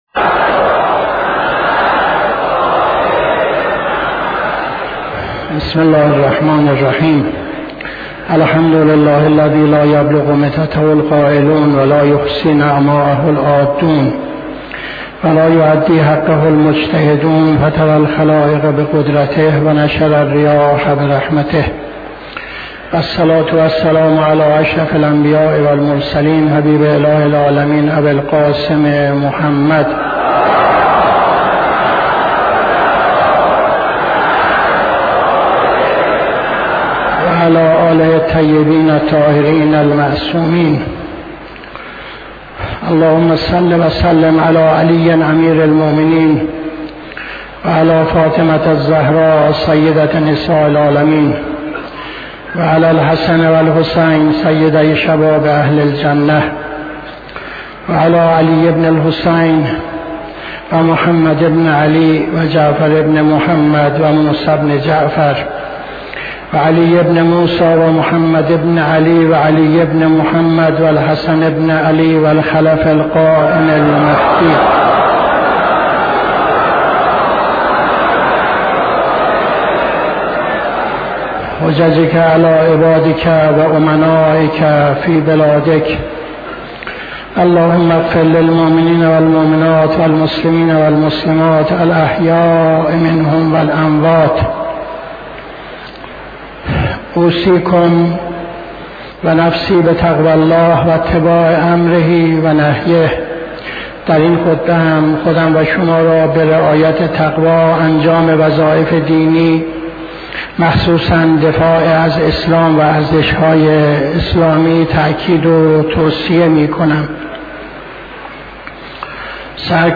خطبه دوم نماز جمعه 31-02-78